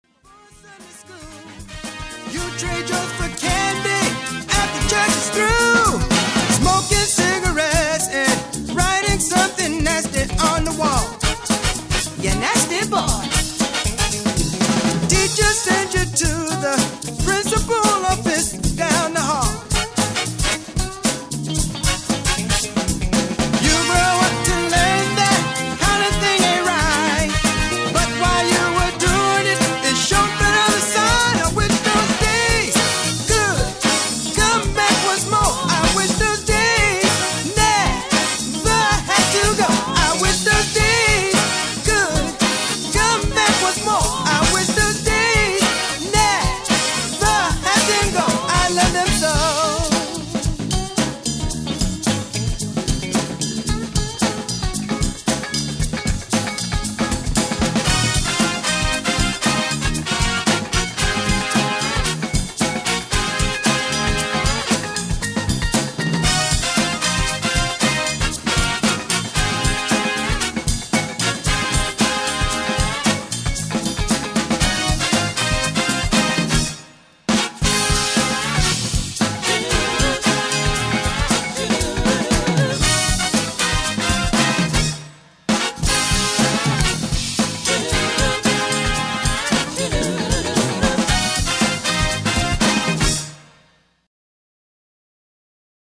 classic soul and funk music from the 60s and 70s